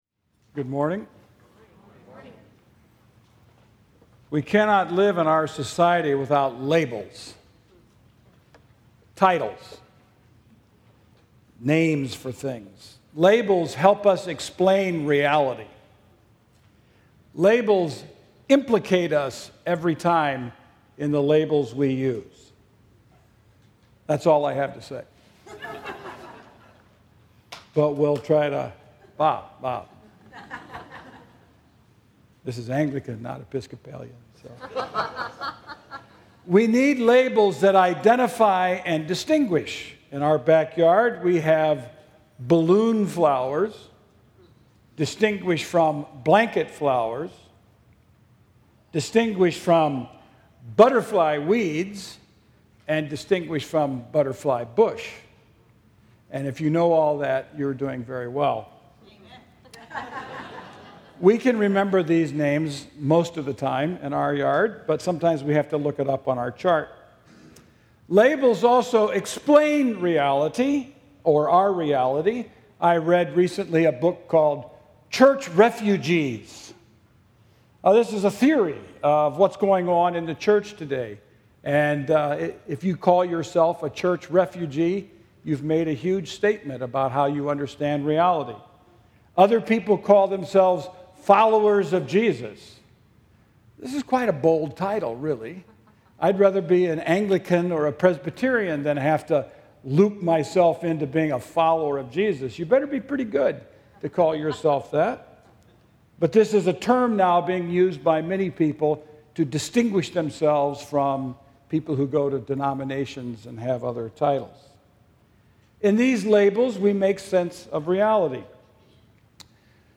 Sermon – Dr. Rev. Scot McKnight